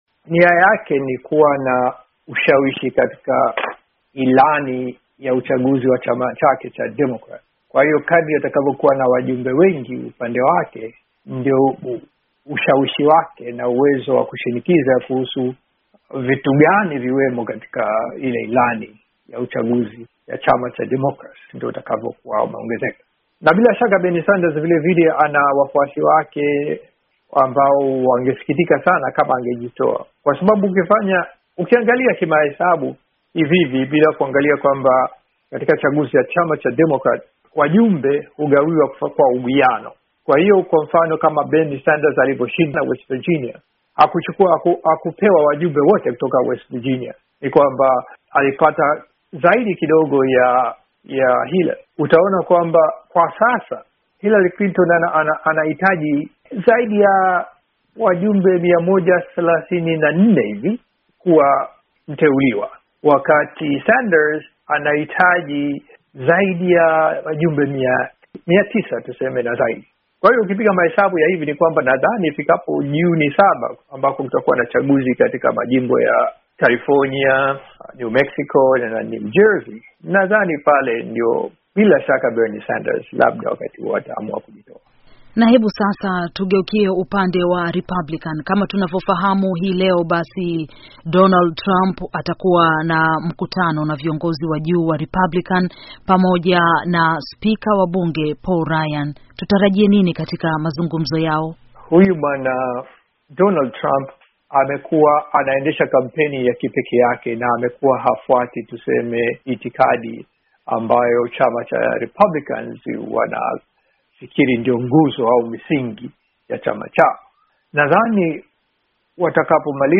Mahojiano